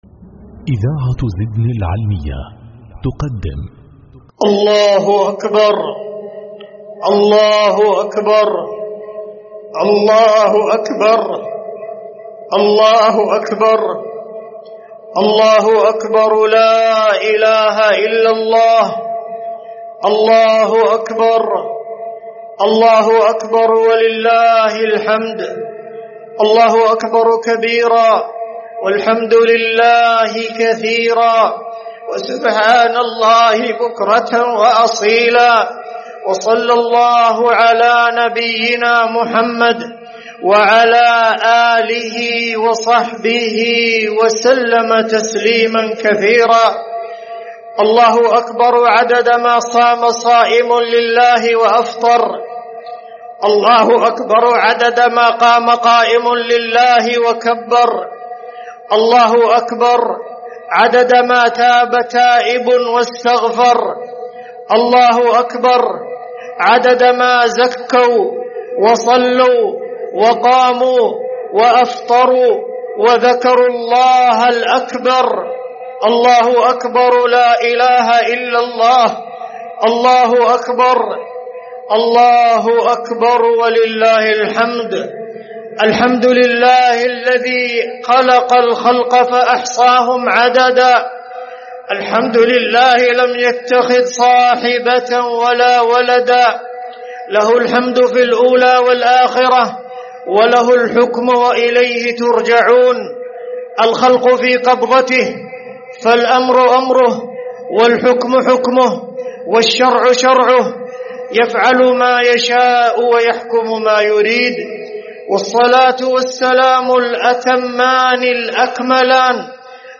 خطبة عيد الفطر المبارك لعام 1446هـ
جامع السودي بمركز القفل بصامطة